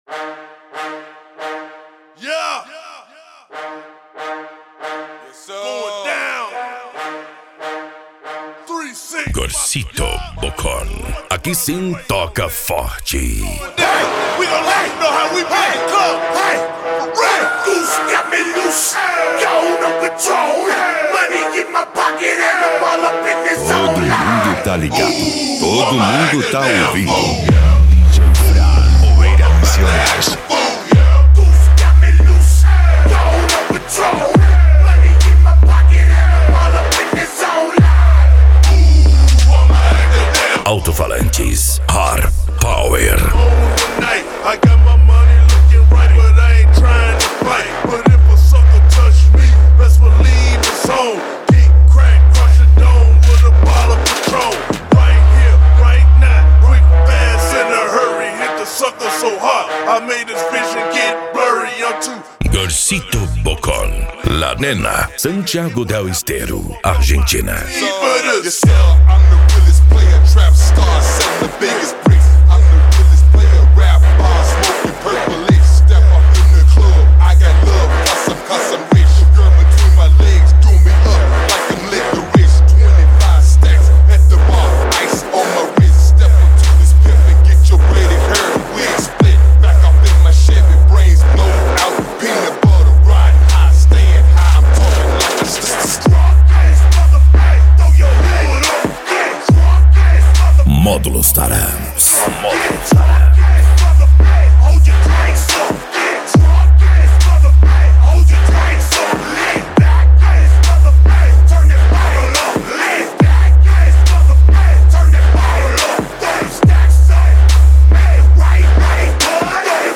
Remix
Bass